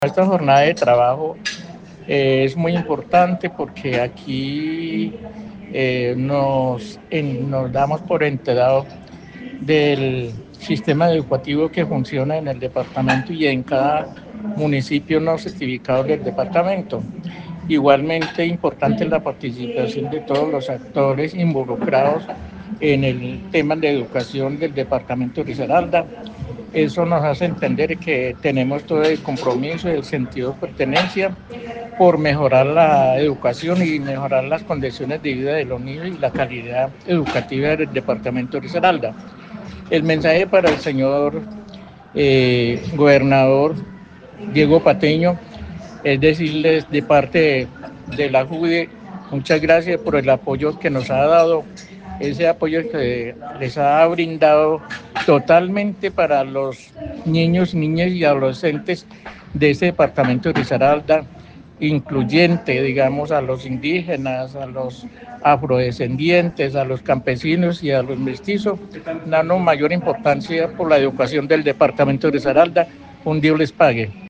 En la reciente Junta Departamental de Educación (JUDE) 2024 se presentó un balance de los logros alcanzados en el sistema educativo de Risaralda, con énfasis en la mejora de la calidad educativa, el bienestar docente y la cobertura.